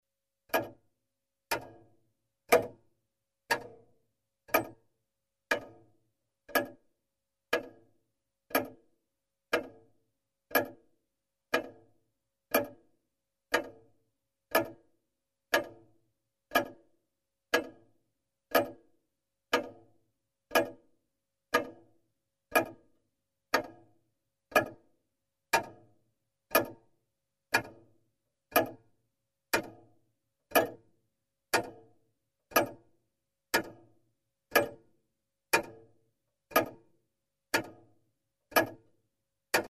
Звуки часов с кукушкой
Звук тиканья старых механических настенных часов